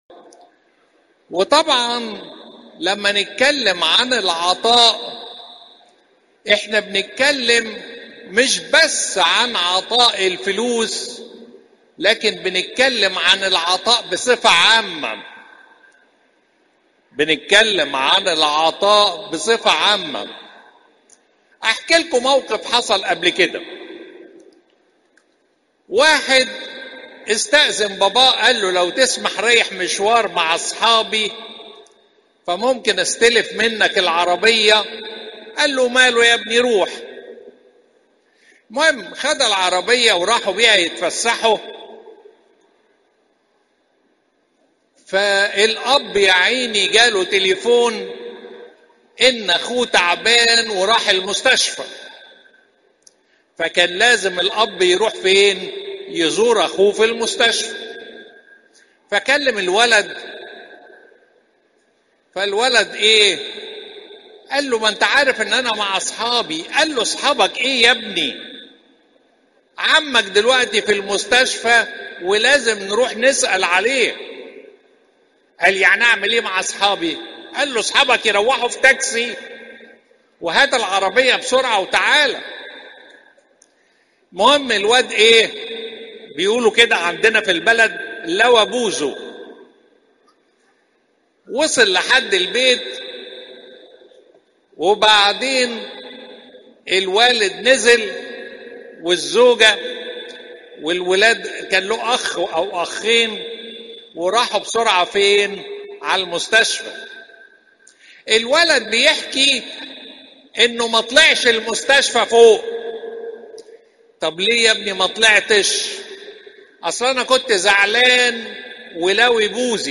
Popup Player تحميل الصوت تحميل الفيديو الانبا مقار الإثنين، 18 أغسطس 2025 43:36 عظات نهضة صوم العذراء (كورنثوس الثانية 9 : 7) (كورنثوس الثانية 9 : 7) الزيارات: 141